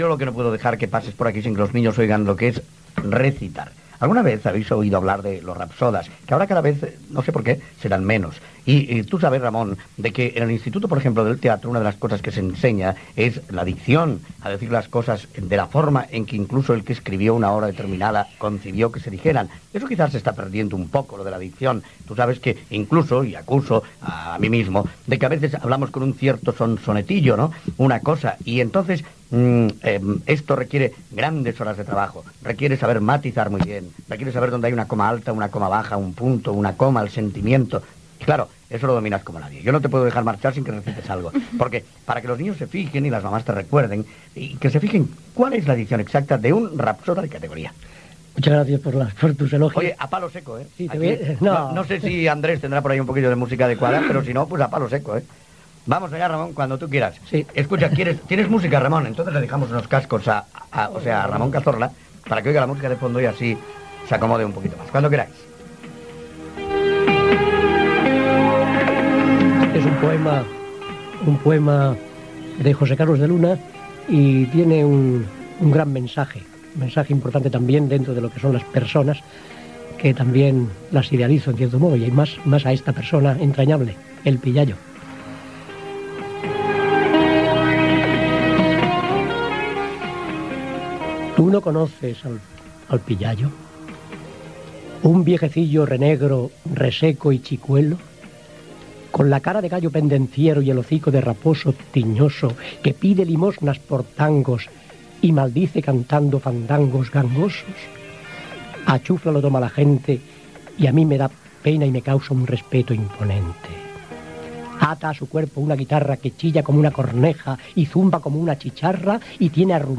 Entrevista
Infantil-juvenil